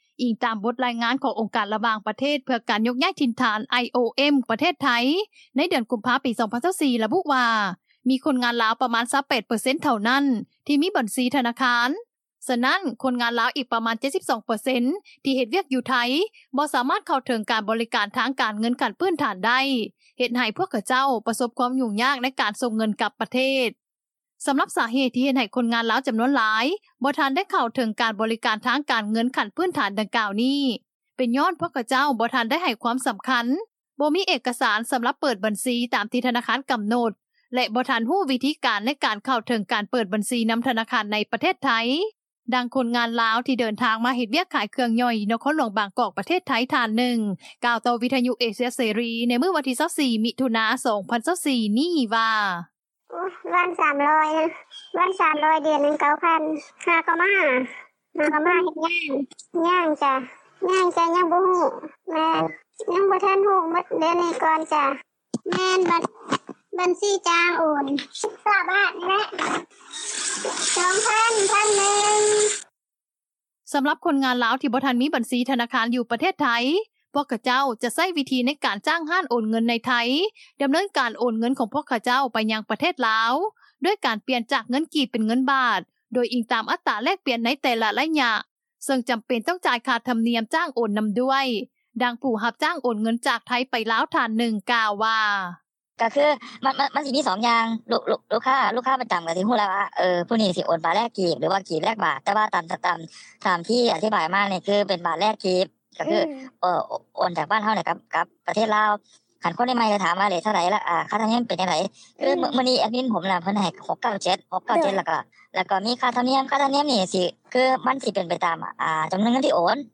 ດັ່ງຄົນງານລາວ ທີ່ເຮັດວຽກ ຂາຍເຄື່ອງຍ່ອຍ ຢູ່ນະຄອນຫຼວງບາງກອກ ປະເທດໄທ ທ່ານໜຶ່ງ ກ່າວຕໍ່ວິທຍຸເອເຊັຽເສຣີ ໃນມື້ວັນທີ 24 ມິຖຸນາ 2024 ນີ້ວ່າ: